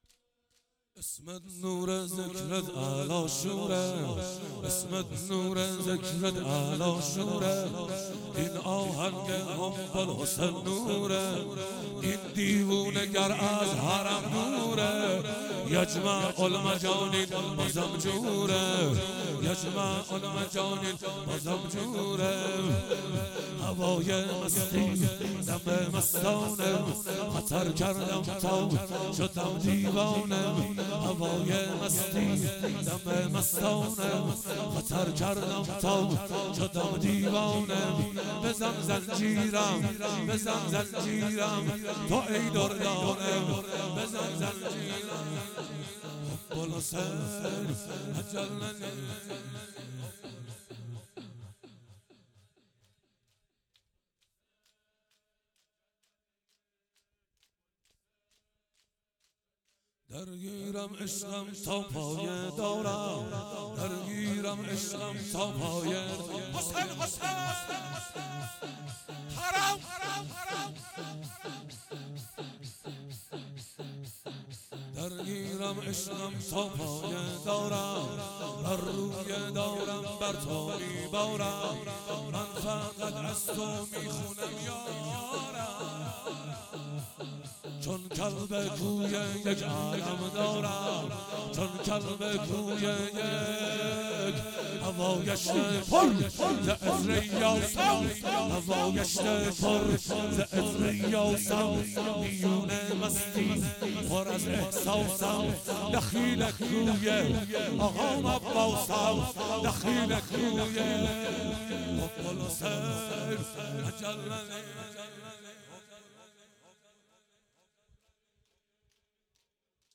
شور
شهادت حضرت زهرا ۷۵ روز ۱۳۹۸